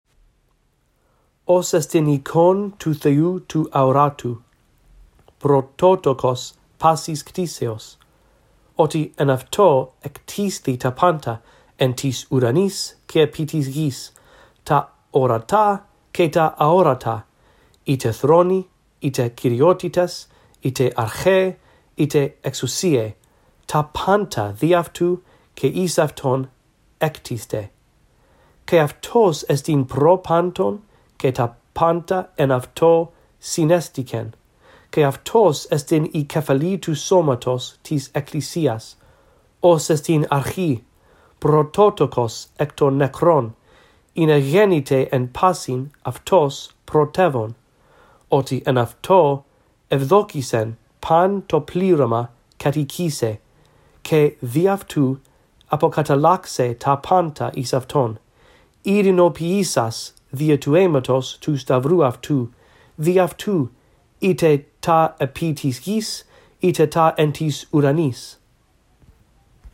1. Listen to me read all of Colossians 1:15–20, following along in the text below.